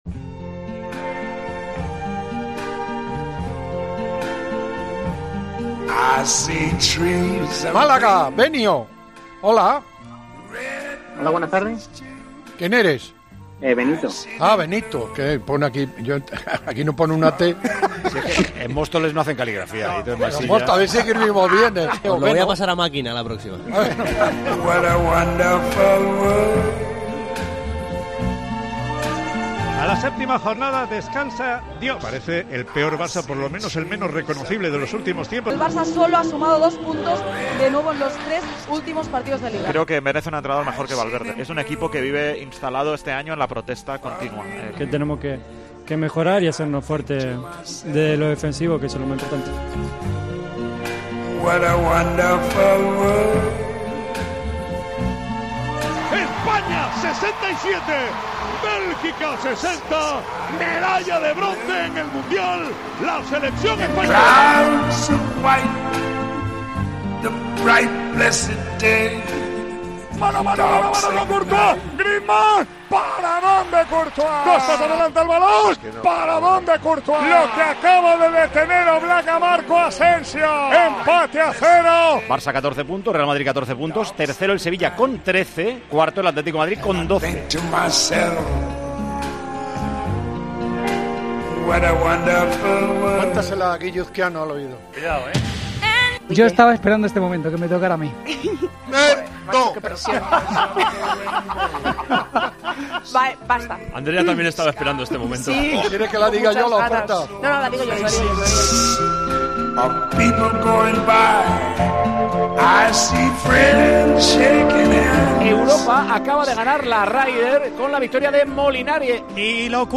La histórica narración